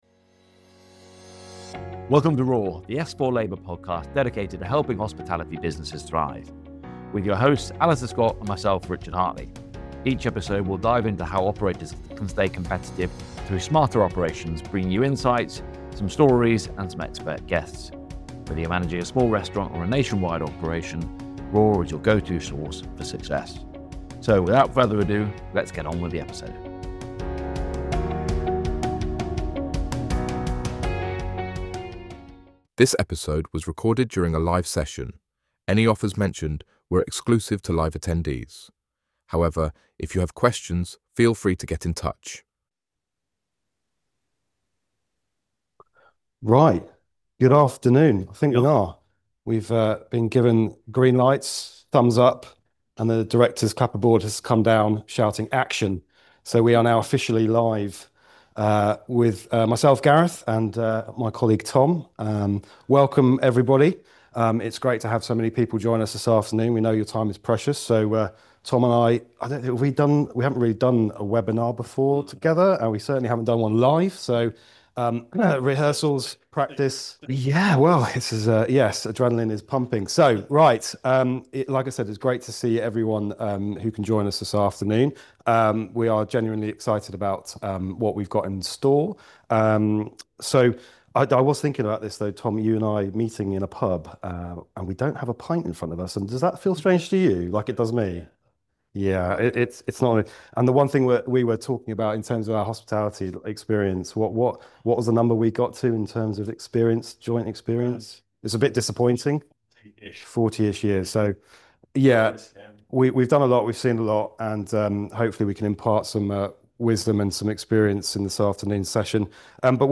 Here’s the replay of our Operational Excellence webinar, specifically for hospitality operators who want to increase margins, without sacrificing service or team morale